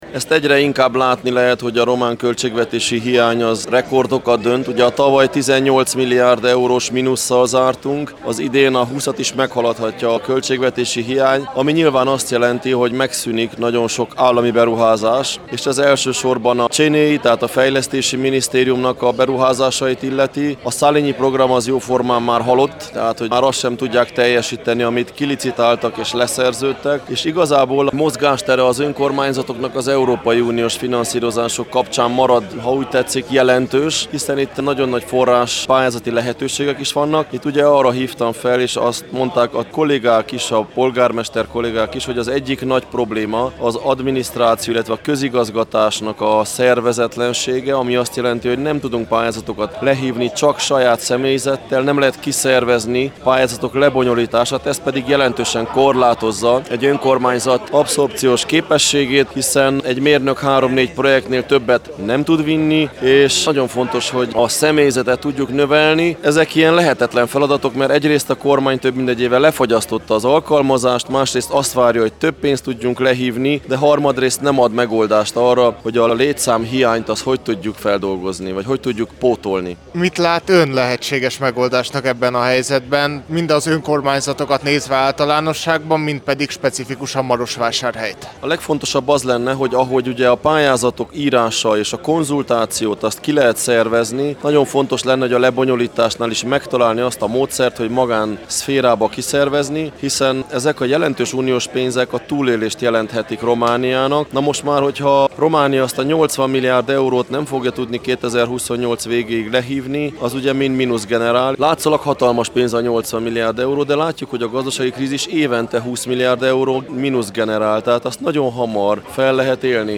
Kerekasztalbeszélgetés a székelyföldi városok polgármestereivel
A 33. Tusványoson tartott önkormányzati kerekasztal-beszélgetésen részt vett, Soós Zoltán, Marosvásárhely polgármestere, Korodi Attila, Csíkszereda polgármestere, Antal Árpád, Sepsiszentgyörgy polgármestere és Szakács-Paál István, Székelyudvarhely frissen megválasztott polgármestere.